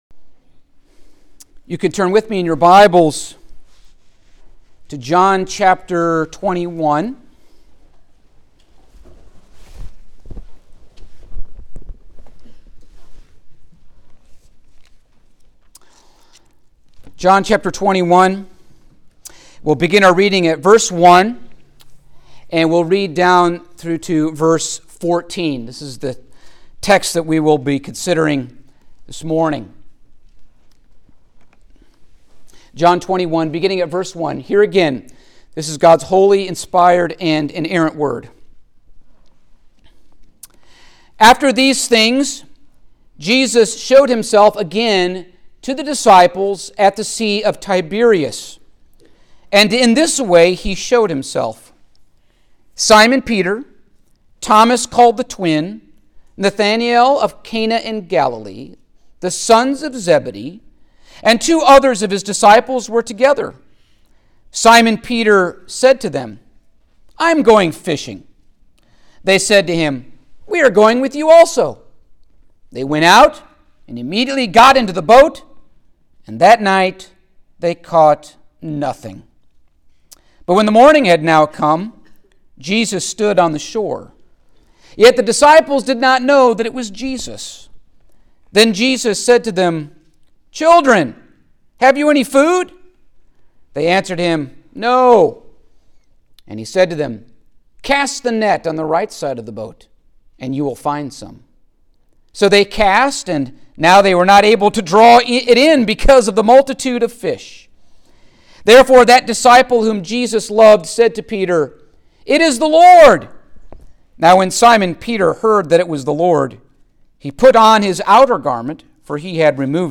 Passage: John 21:1-14 Service Type: Sunday Morning